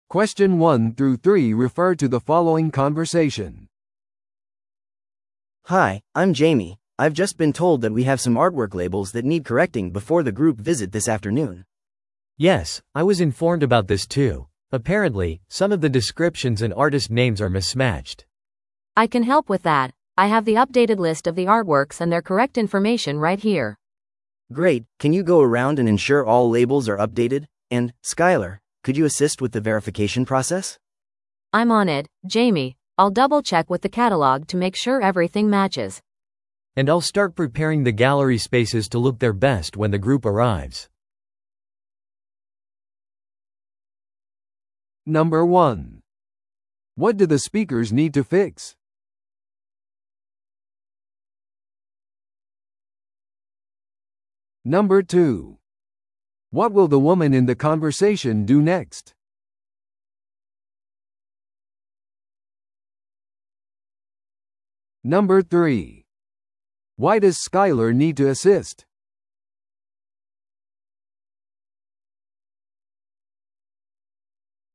No.2. What will the woman in the conversation do next?